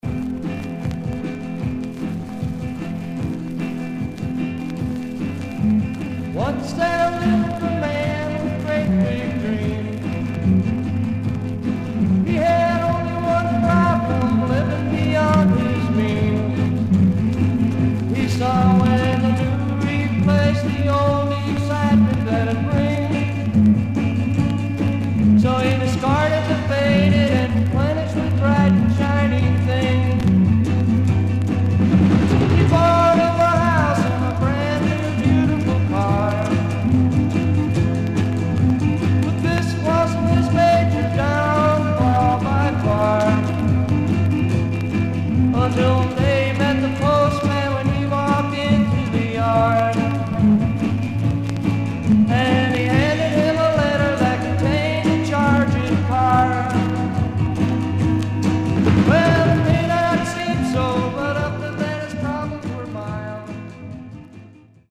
Stereo/mono Mono
Garage, 60's Punk Condition